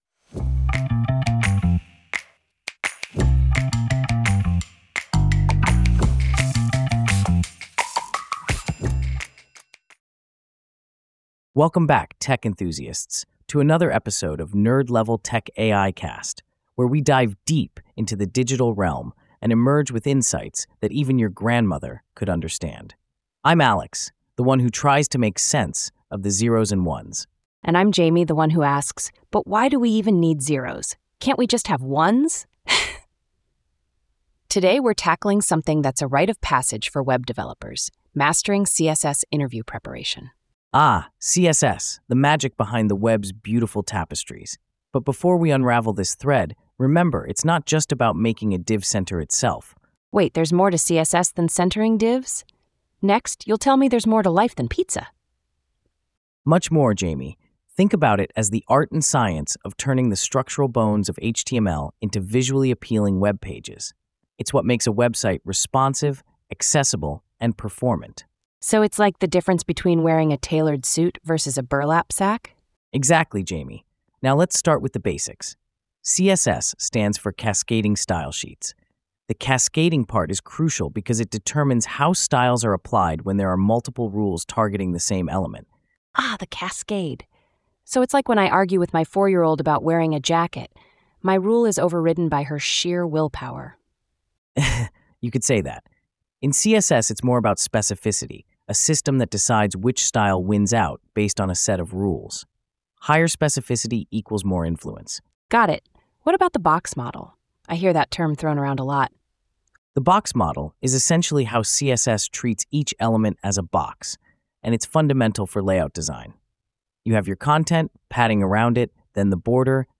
AI-generated discussion by Alex and Jamie